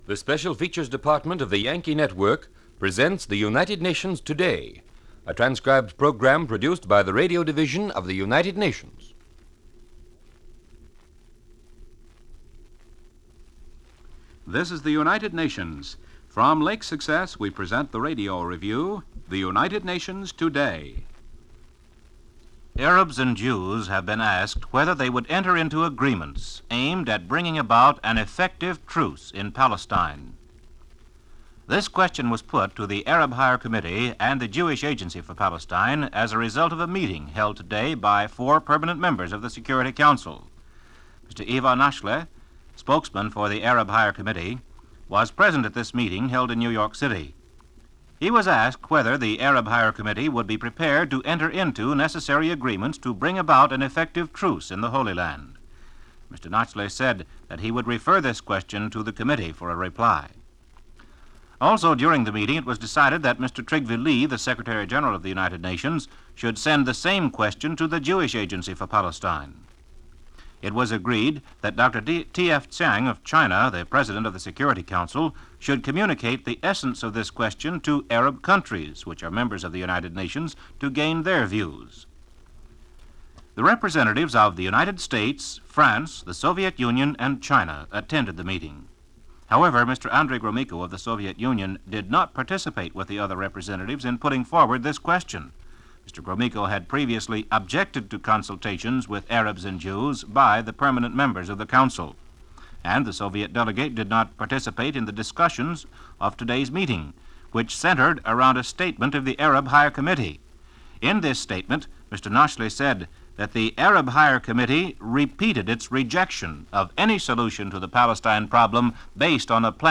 Maarch 15, 1948 - United Nations - The View From Lake Success - Issues facing the world body in this daily report via UN Radio - Past Daily Reference Room.